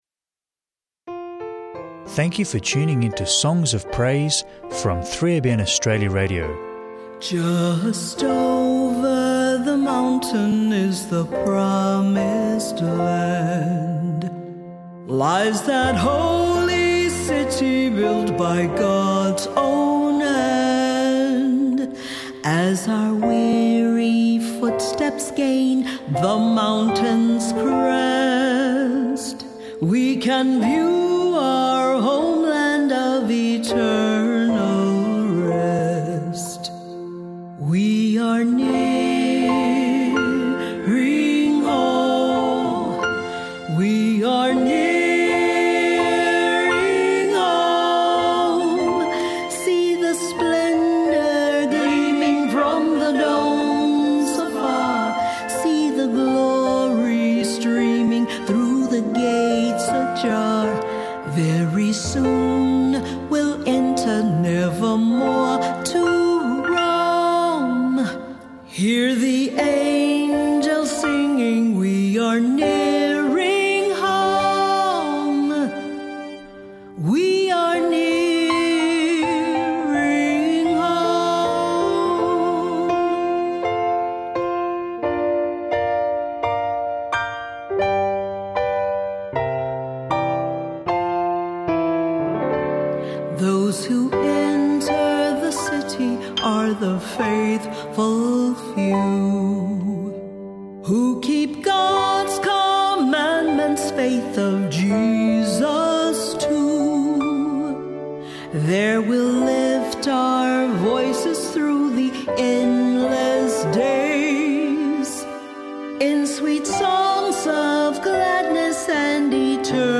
Book Reading